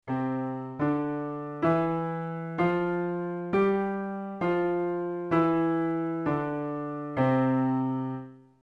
There is an entire array of songs in different styles that can be played upon the piano, depending on the creativity skill level of the pianist.
Scale in C Traditional 0:09